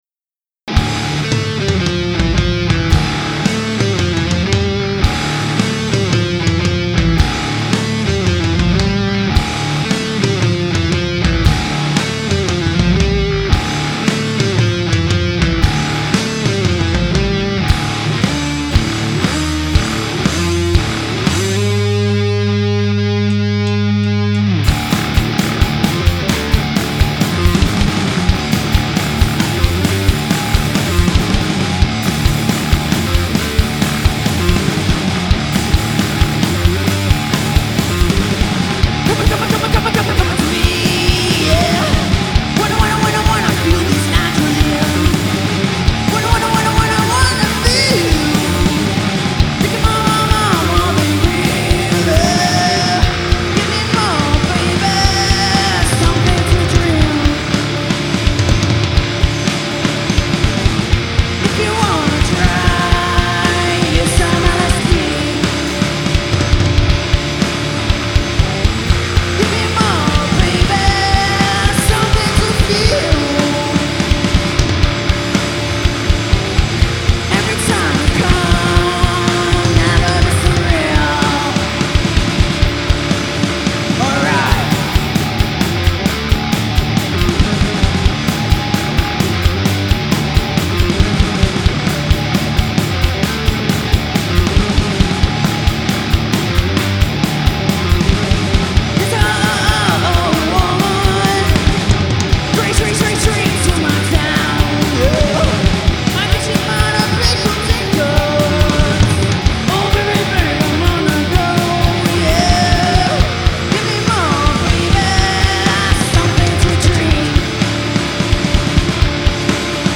com baixo